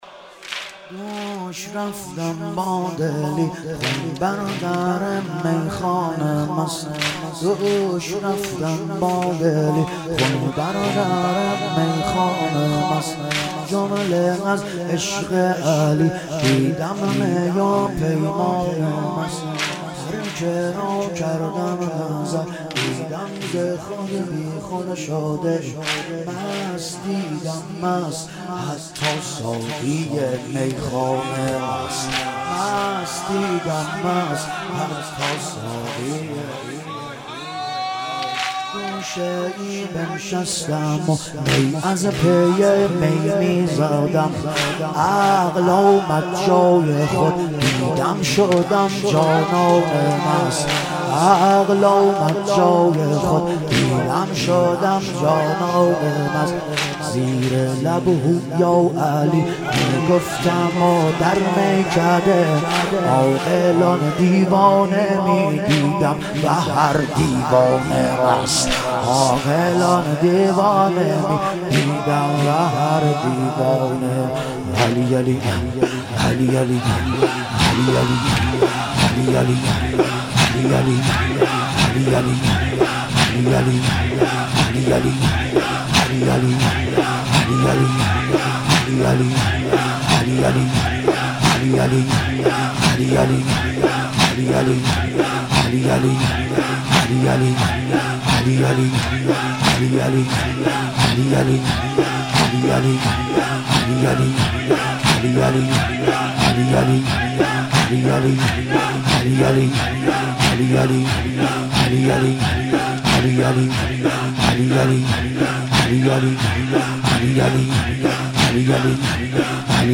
ایام فاطمیه اول - واحد